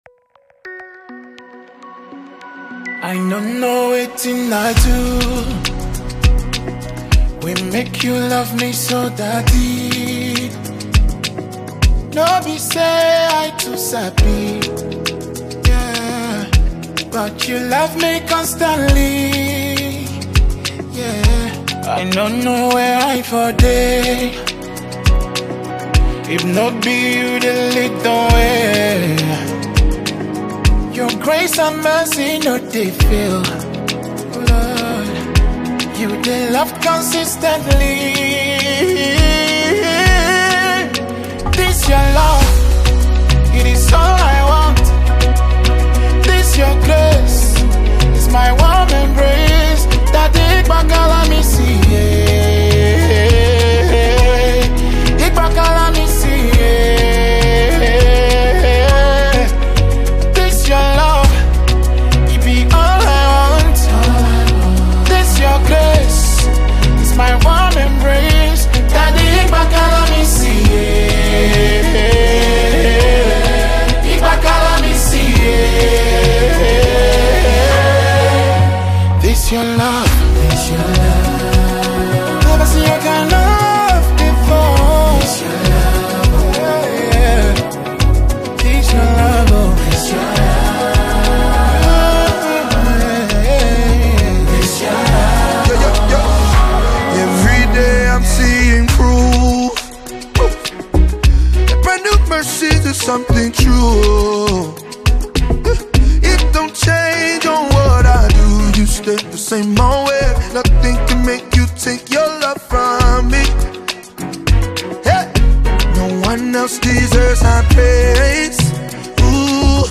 Nigeria talented gospel music singer